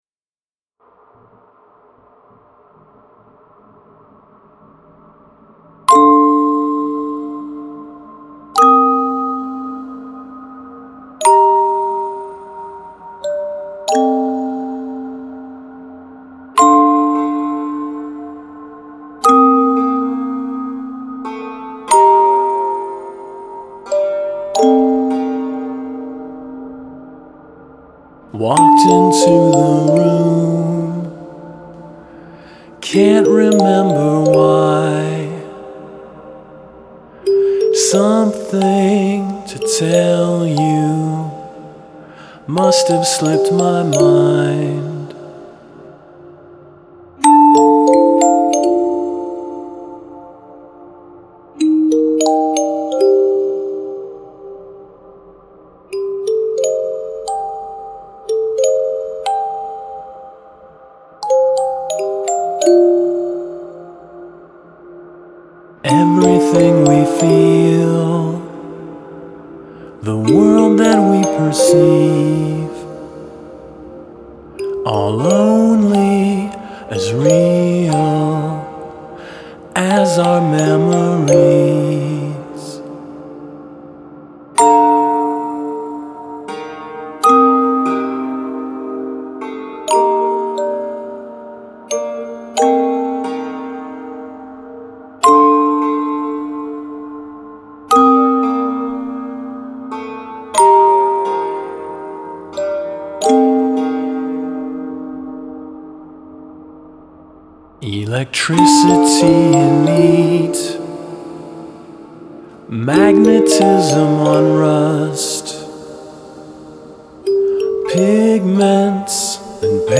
Stark and minimal. All of the sounds are acoustic or physical in origin - there are no synthesizers or electric guitars, drum machines, or drum kits. The closest thing to that is electric piano.